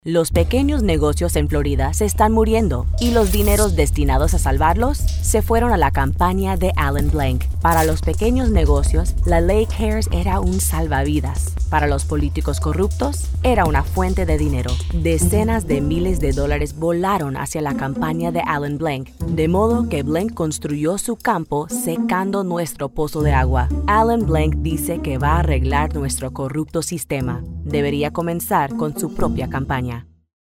Female Spanish Republican Political Voiceover
Spanish attack ad